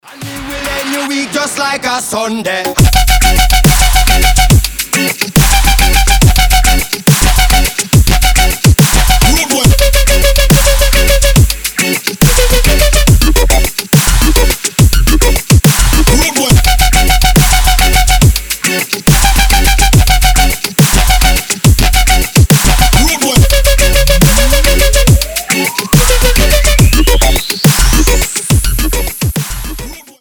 дабстеп , басы , регги , качающие